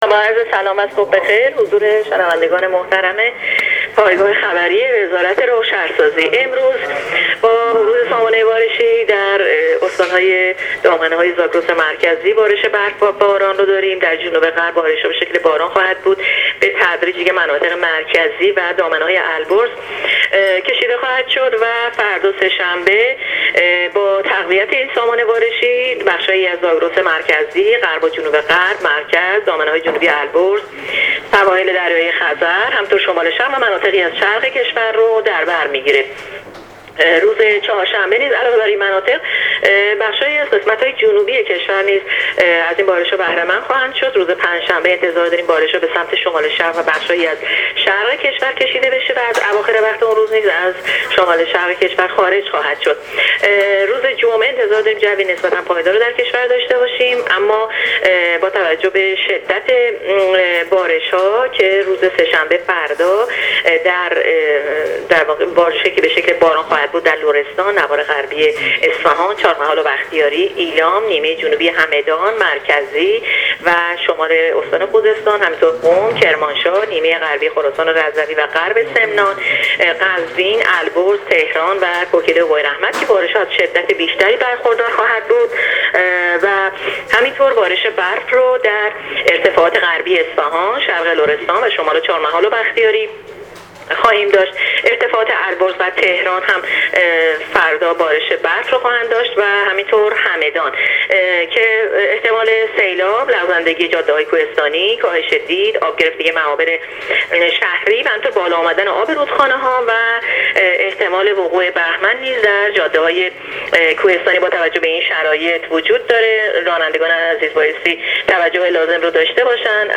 گزارش رادیو اینترنتی از آخرین وضعیت آب و هوای پنجم اسفندماه ۱۳۹۸